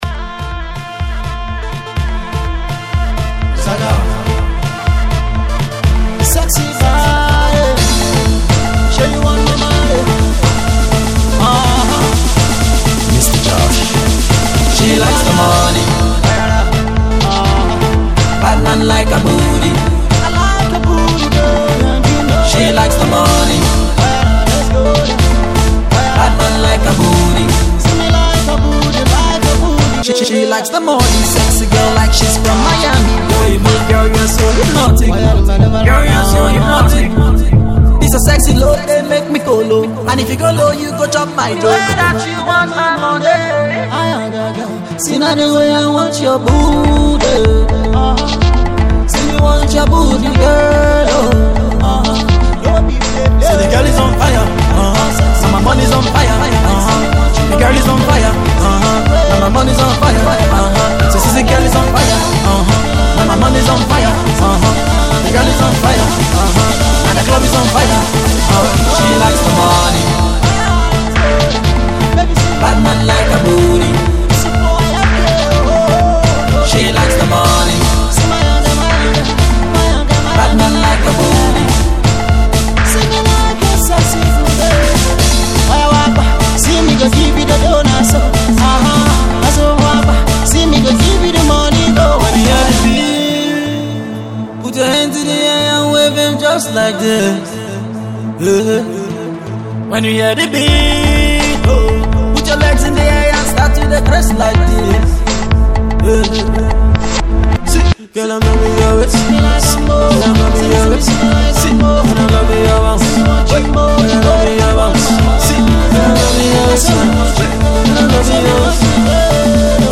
another one for the clubs